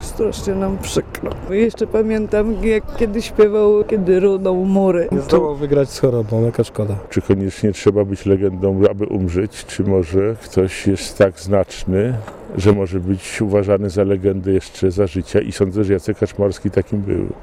Warszawiacy o Kaczmarskim